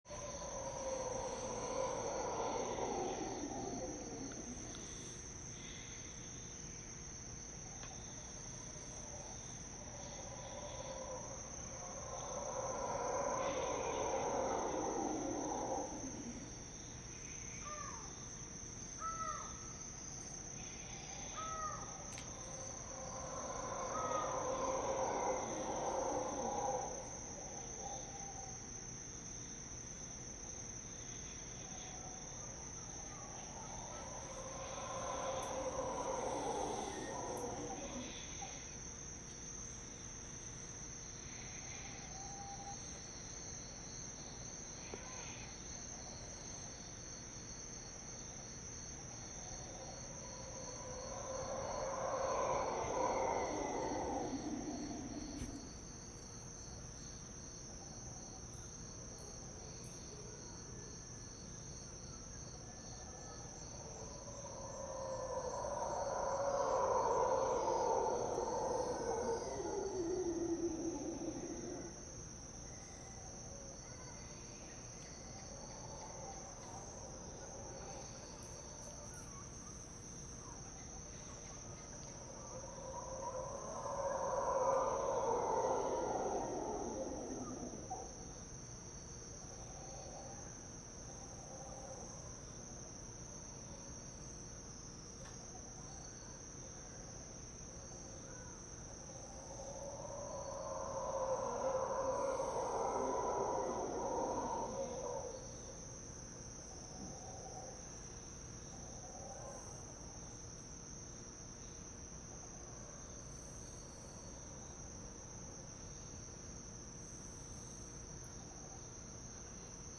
We recorded sounds of the jungle. We recommend ear phones and volume.